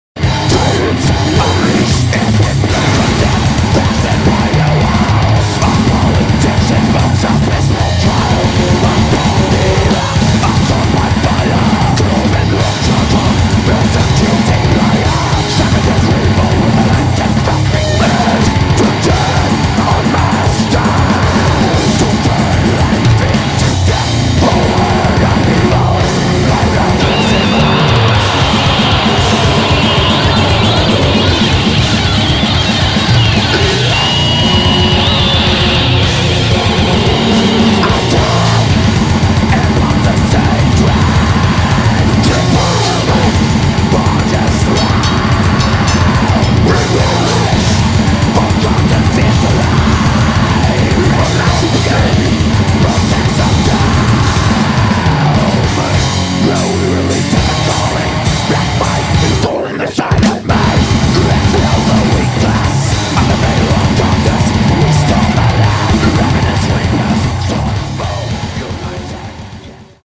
Australian relentless Black Death Metal